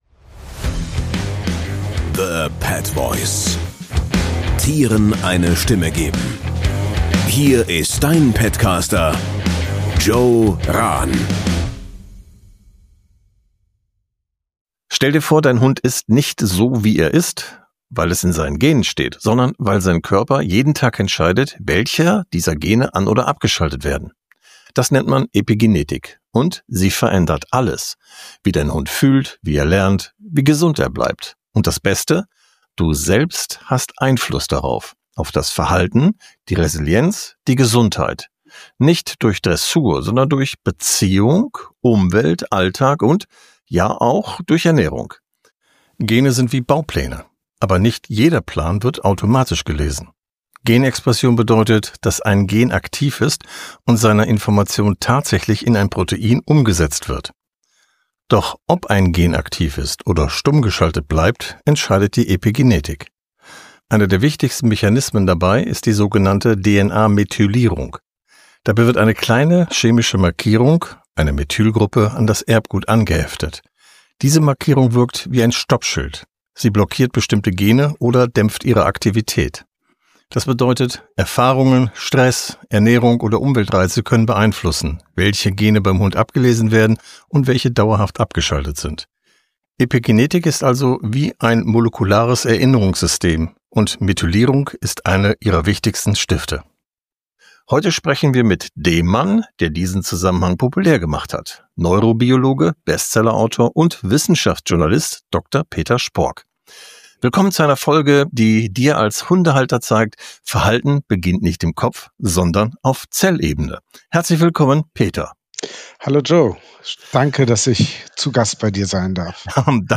#17 - Die Epigenetik des Hundes - Interview